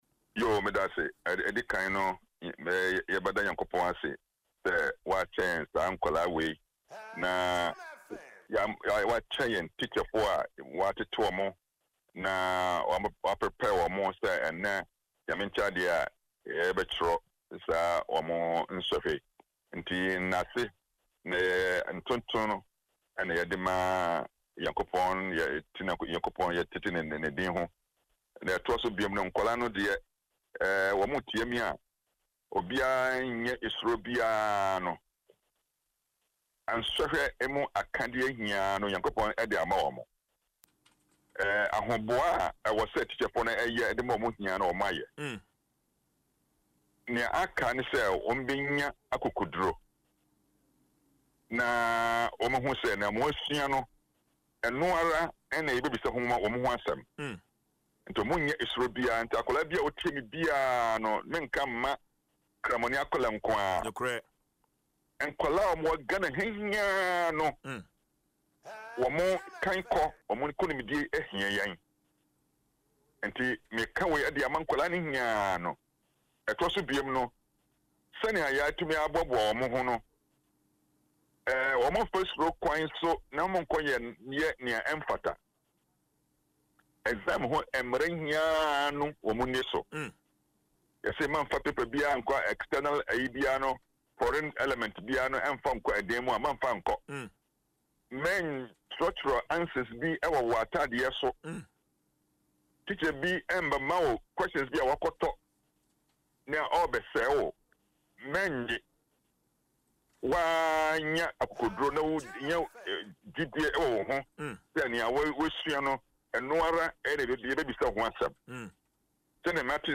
Speaking in an interview on Adom FM’s morning show Dwaso Nsem